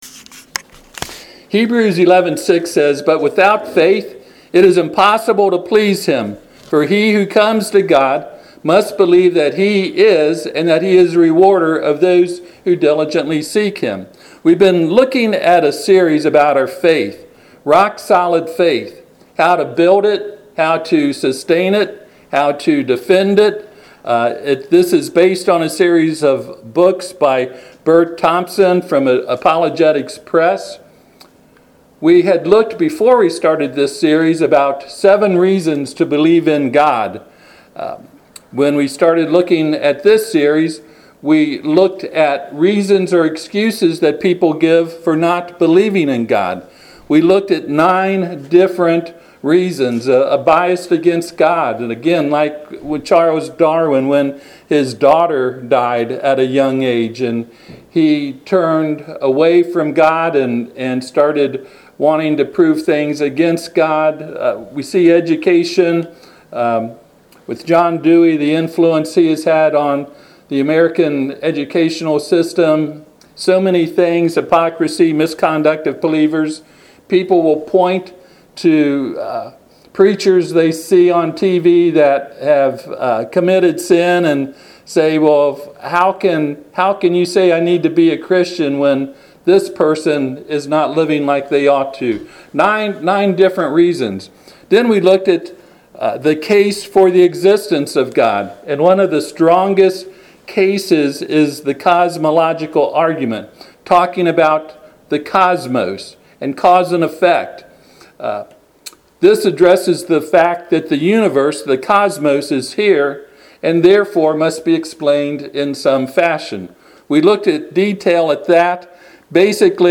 Passage: Romans 1:20-21 Service Type: Sunday PM